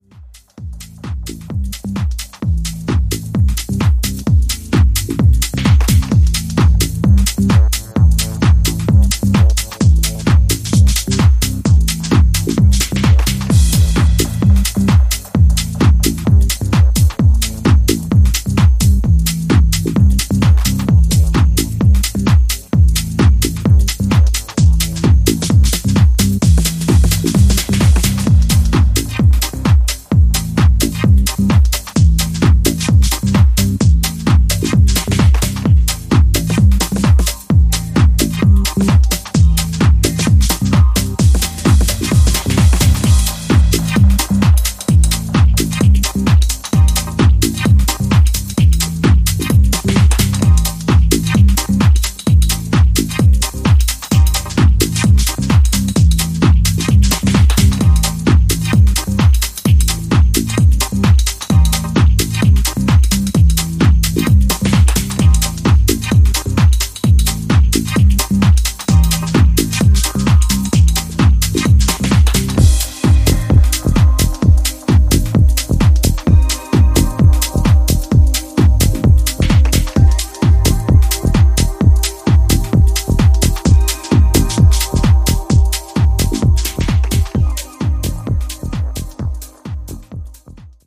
落ち着いたトーンをキープしながら厚いシンセベースとタイトなキックでフロアをグイグイ引っ張る